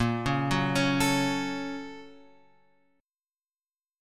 A# Minor Major 7th Sharp 5th